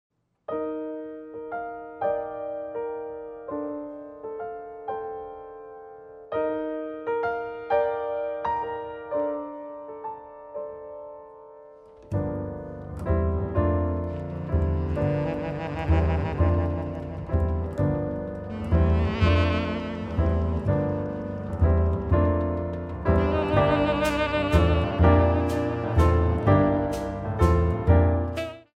Alto Sax, Piano, Bass, Percussion
Improvisation is the cloth of these works are cut from.